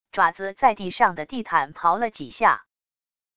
爪子在地上的地毯刨了几下wav音频